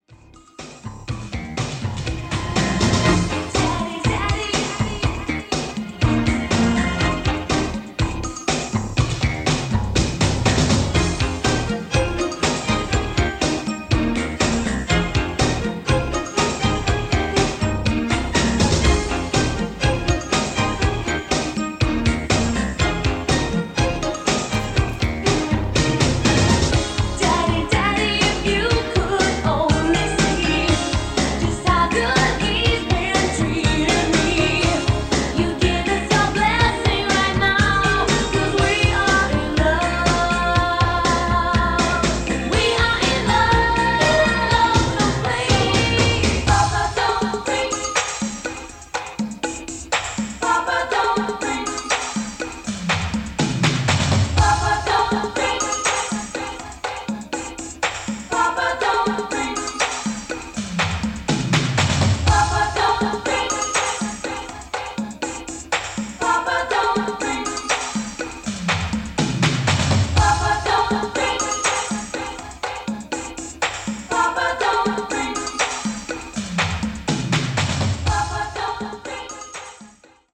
Below is a test recording made with the AD-R600 and played back by it on a normal position tape: